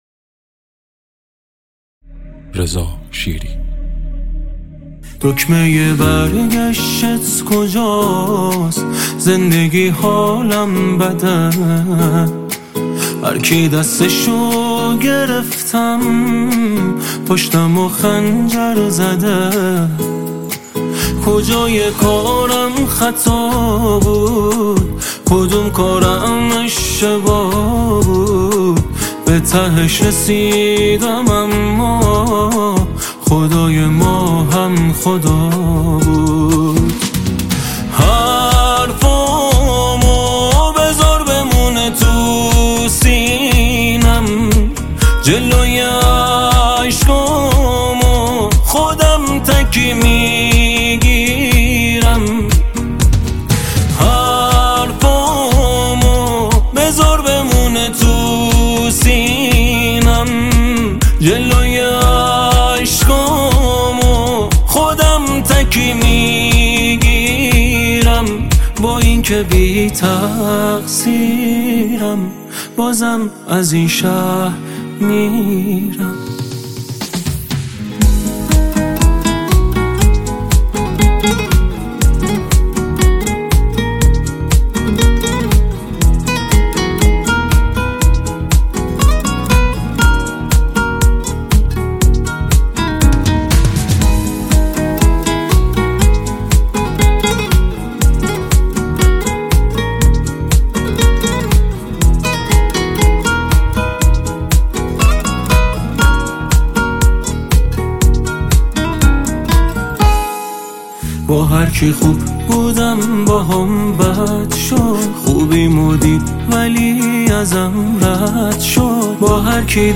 پاپ غمگین